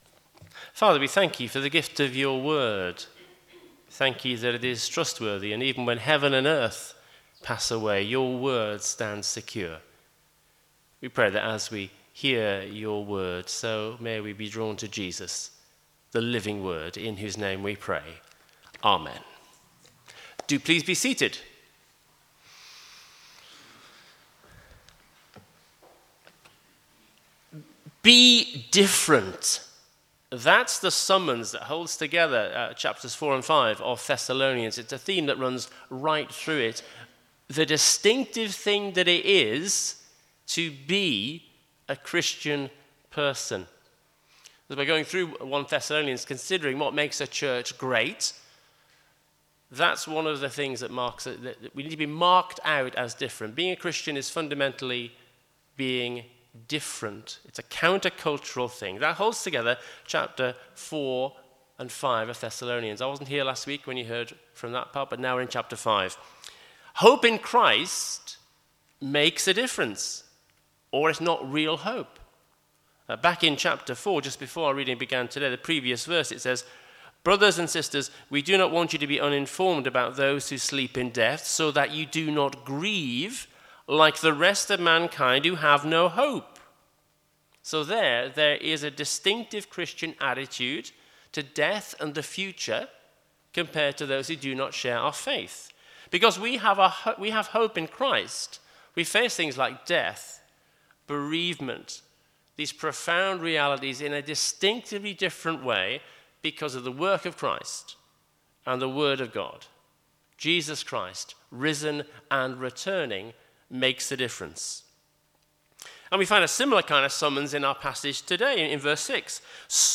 Sermons – Page 23 – St Marks Versailles